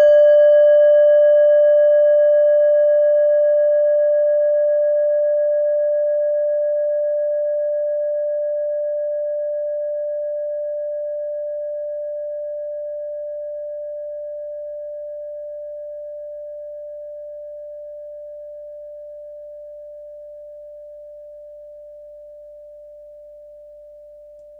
Klangschalen-Typ: Bengalen
Klangschale Nr.6
Gewicht = 320g
Durchmesser = 10,7cm
(Aufgenommen mit dem Filzklöppel/Gummischlegel)
klangschale-set-1-6.wav